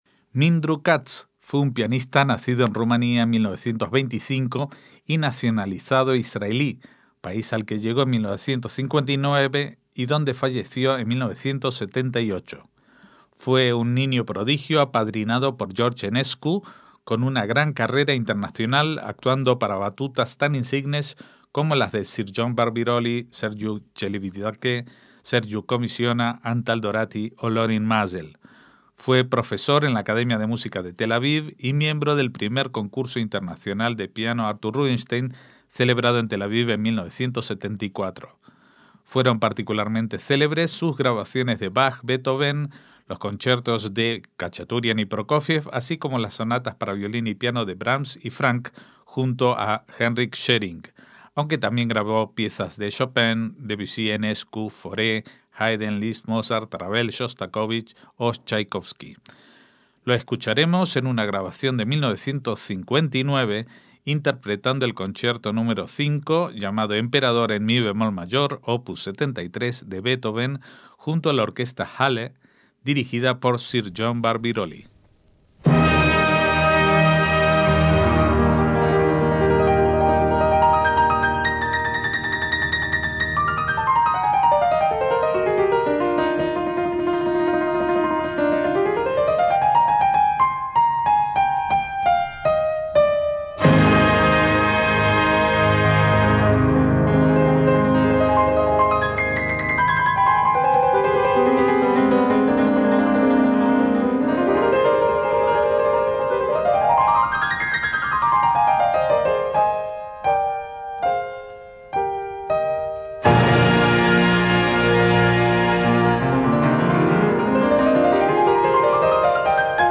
MÚSICA CLÁSICA
Concierto para piano y orquesta n.º 5 en mi bemol mayor, op. 73
Lo oiremos en la interpretación solista de Mindru Katz, pianista israelí nacido en Rumania en 1925 y fallecido en 1978.